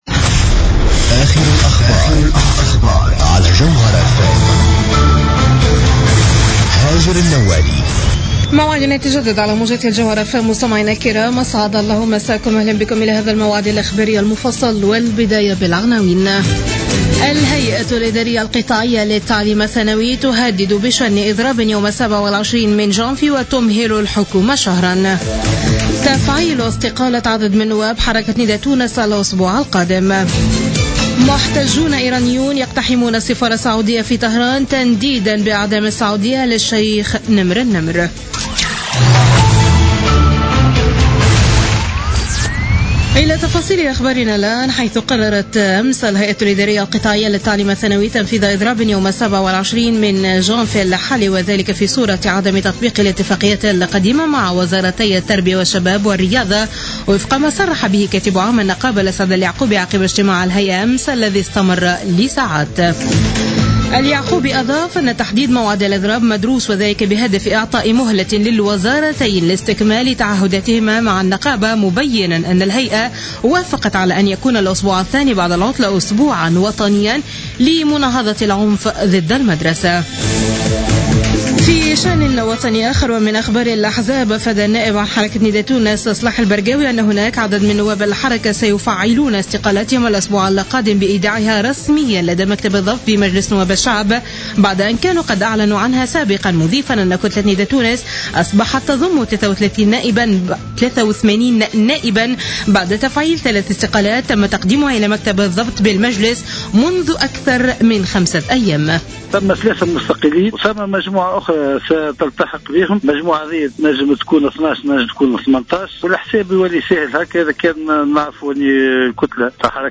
نشرة أخبار منتصف الليل ليوم الأحد 3 جانفي 2016